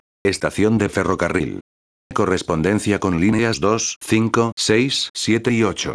1-estación_de_ferrocarril.wav